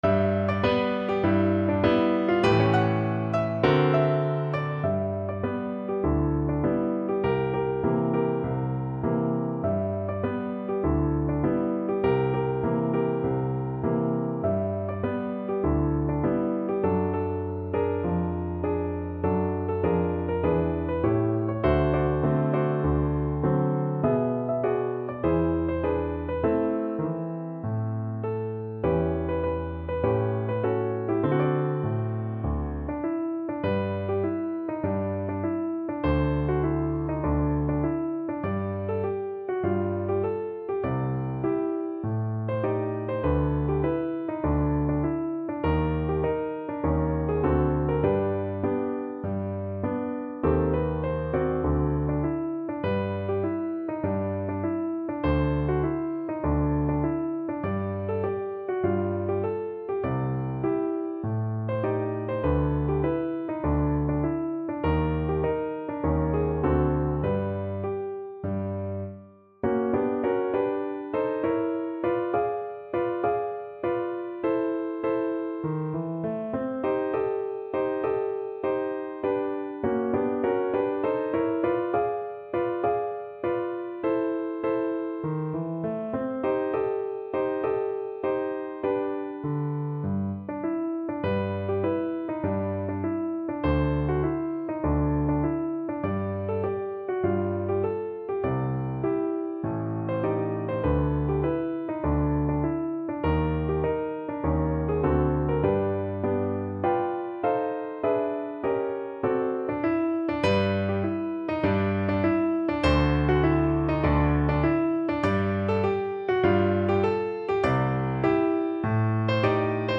Piano version
No parts available for this pieces as it is for solo piano.
4/4 (View more 4/4 Music)
Pop (View more Pop Piano Music)